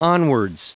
Prononciation du mot onwards en anglais (fichier audio)
Prononciation du mot : onwards